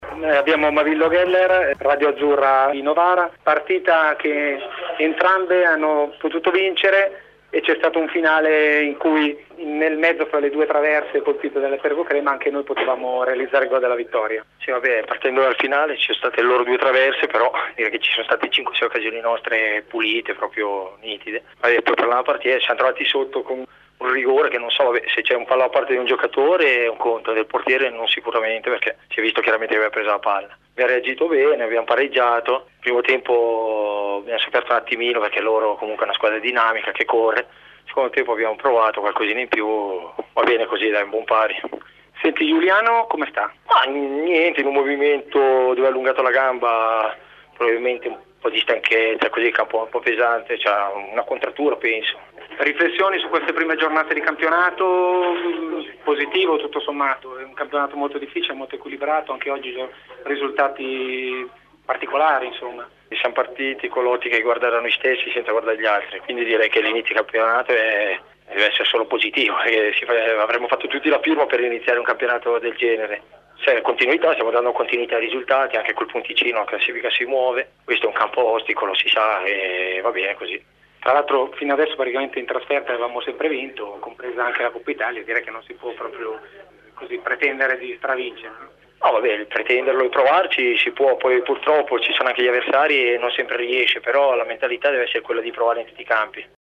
ai microfoni di Radio Azzurra fm.